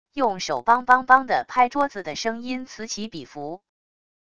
用手梆梆梆的拍桌子的声音此起彼伏wav音频